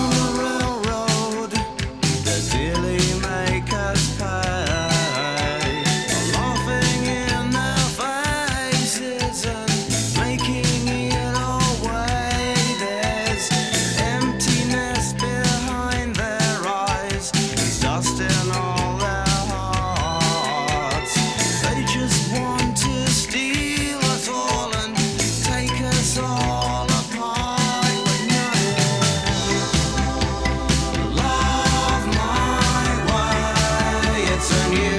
Alternative Rock Adult Alternative New Wave College Rock
Жанр: Рок / Альтернатива